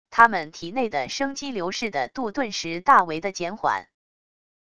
他们体内的生机流逝的度顿时大为的减缓wav音频生成系统WAV Audio Player